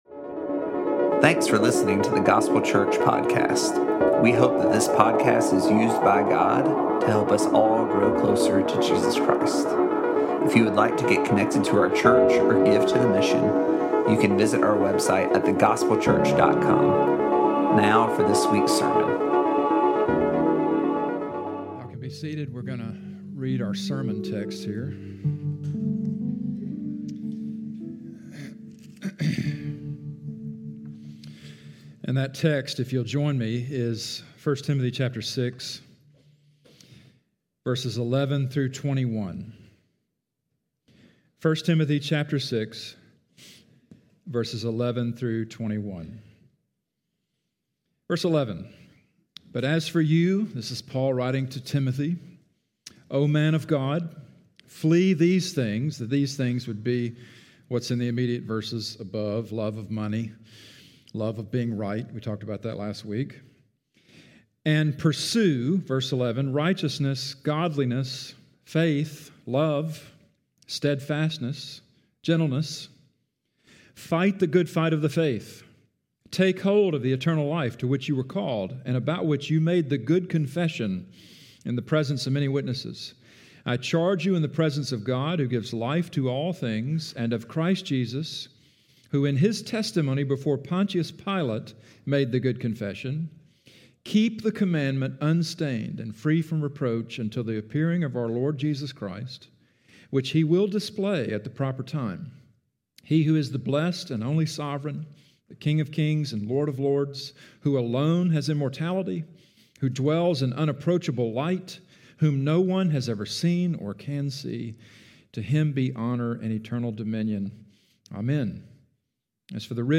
Today is our concluding message in our series, “A Godly Life,” through 1 Timothy. A godly life requires effort, not earning, and today’s passage shows us what shape that effort takes.